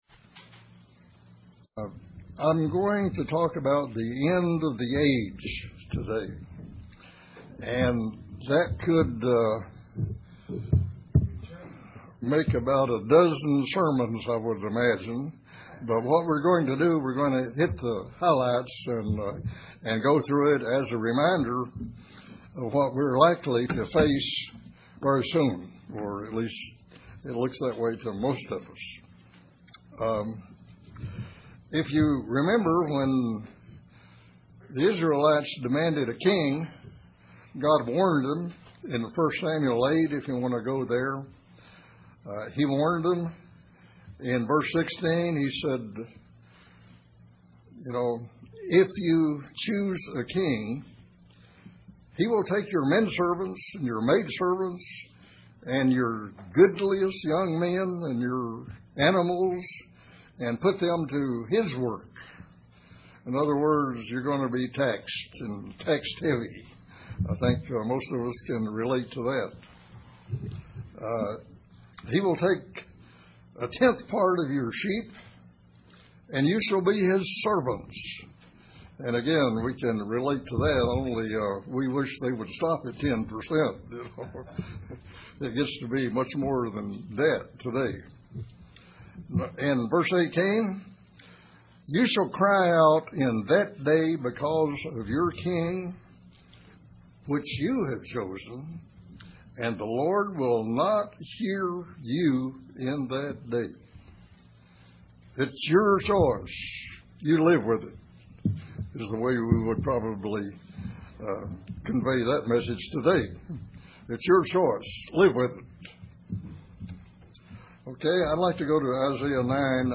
Many questions, many answers in this sermon.